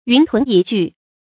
云屯蟻聚 注音： ㄧㄨㄣˊ ㄊㄨㄣˊ ㄧˇ ㄐㄨˋ 讀音讀法： 意思解釋： 形容眾多的人物聚集在一起。